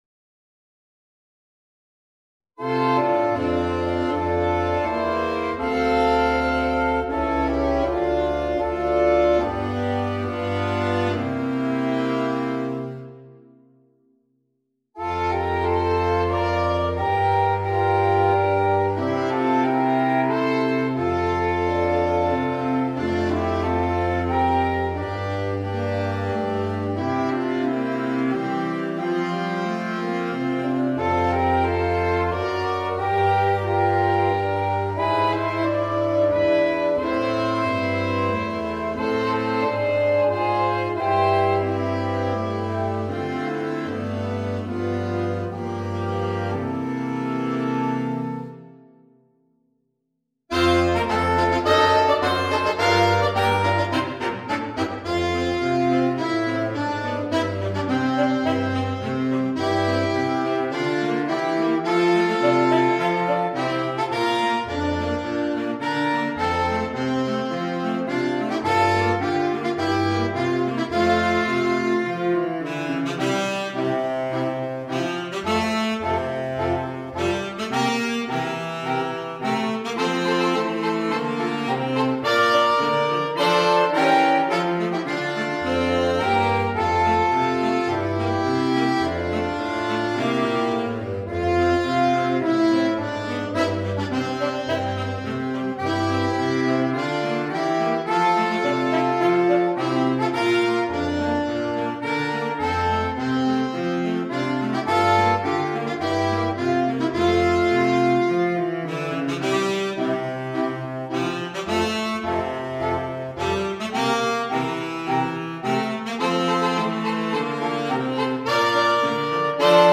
for Saxophone Quartet SATB or AATB